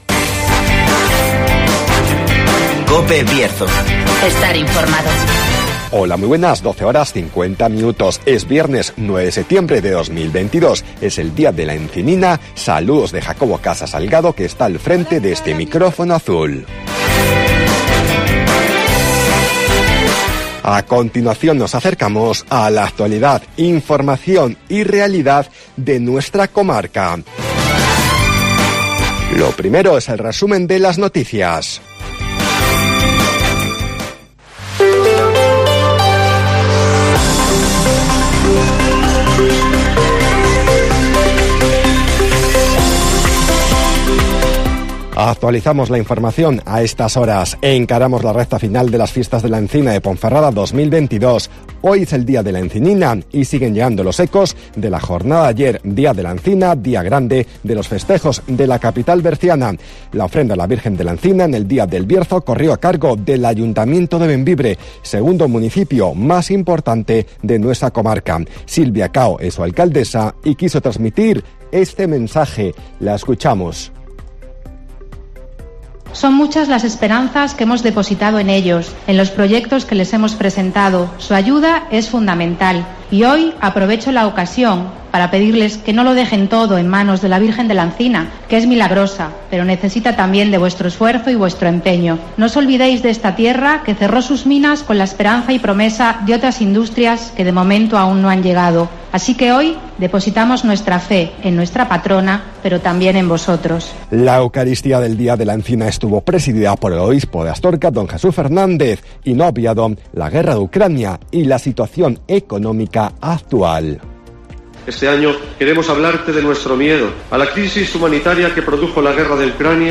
AUDIO: Resumen de las noticias, el tiempo y la agenda